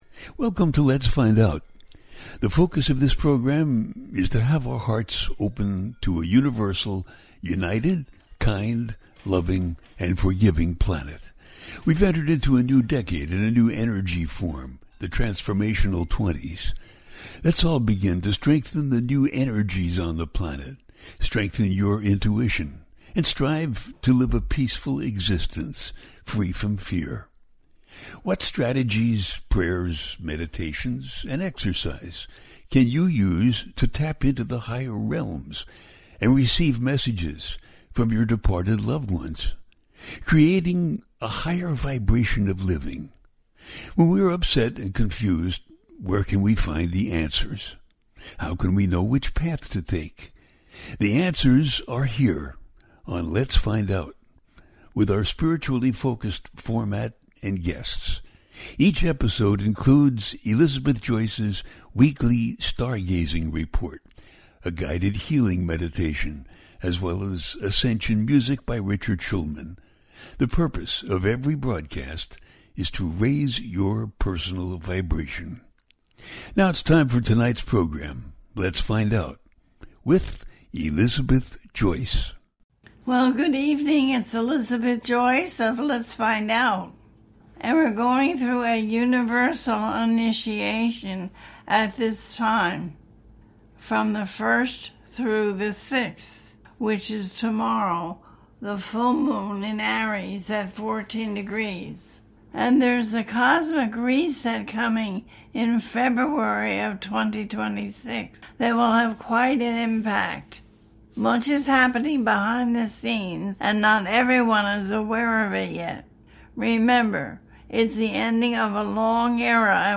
October 2025 Begins The Great Reset - A teaching show